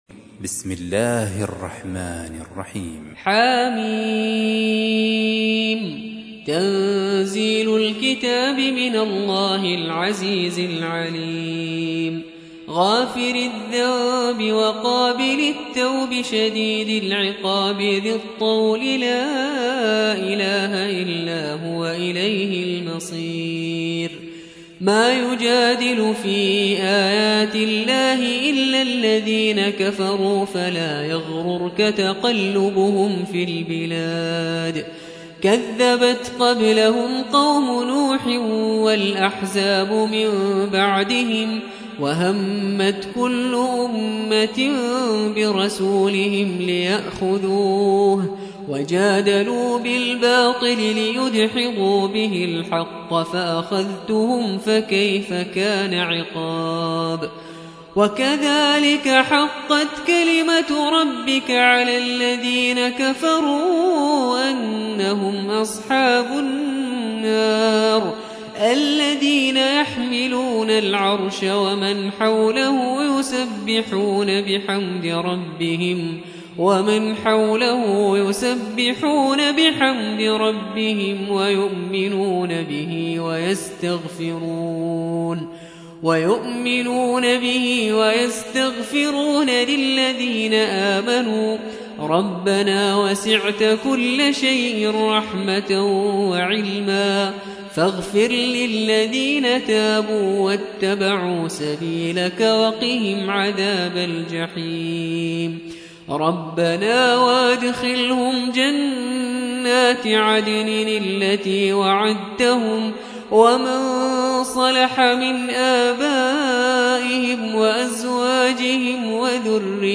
40. سورة غافر / القارئ